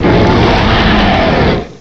cry_not_zygarde.aif